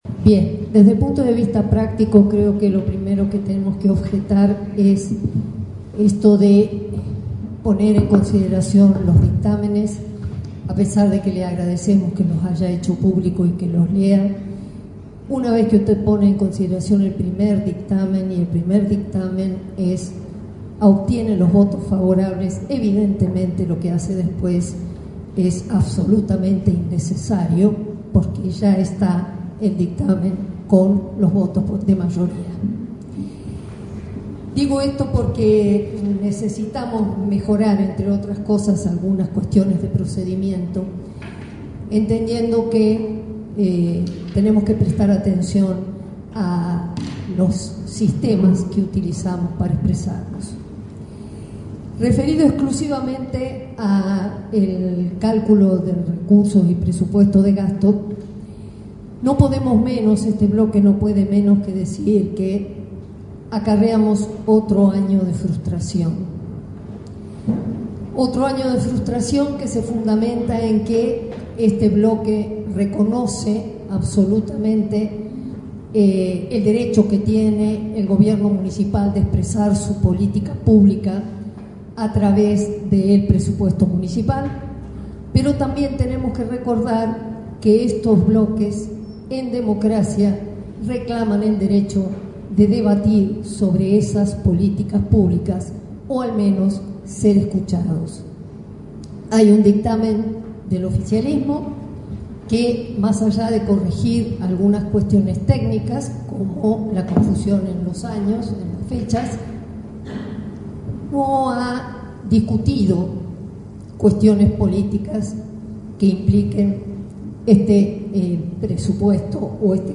En la sesión del lunes por la noche, la concejal Susana Marcolini fundamentó el voto negativo del bloque radical al Presupuesto 2026. Durante su alocución, la legisladora fue crítica con el incremento de la presión tributaria y señaló que el cálculo de recursos presentado por el Ejecutivo de Estefanía Bordoni no refleja las prioridades de los contribuyentes del distrito.